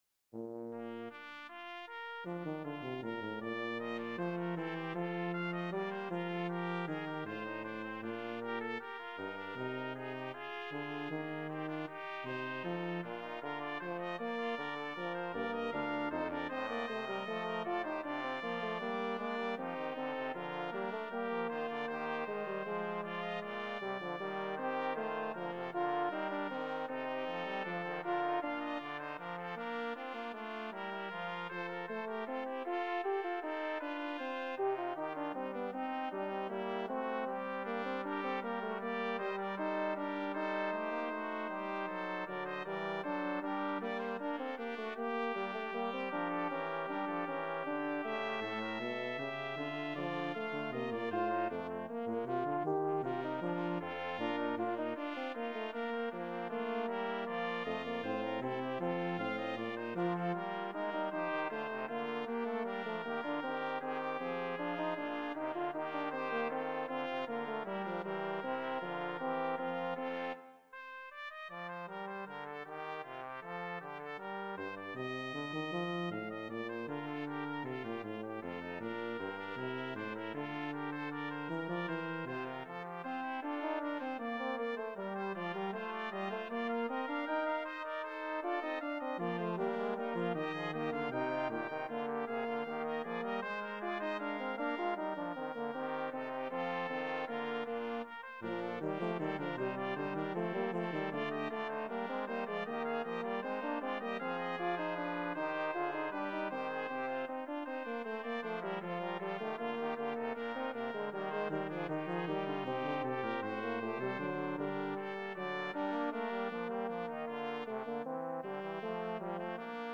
BRASS QUINTET
FOR STANDARD BRASS QUINTET